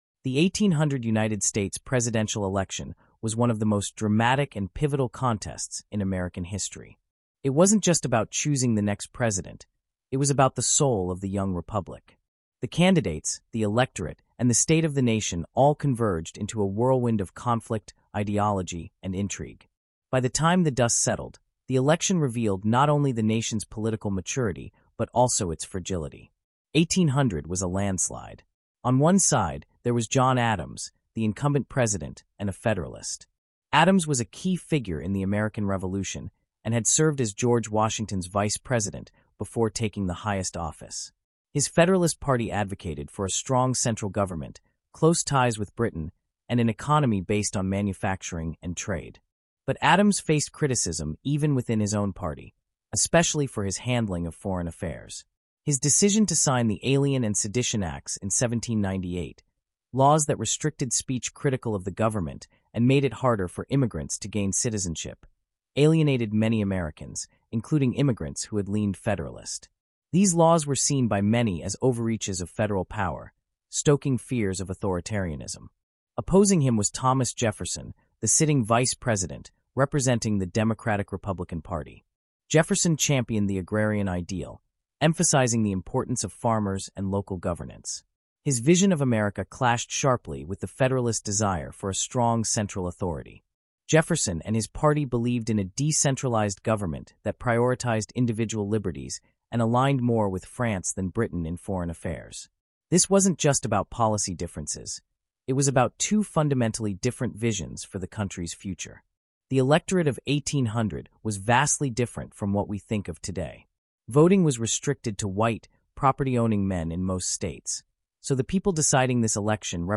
Disclosure: This podcast includes content generated using an AI voice model. While efforts were made to ensure accuracy and clarity, some voices may not represent real individuals.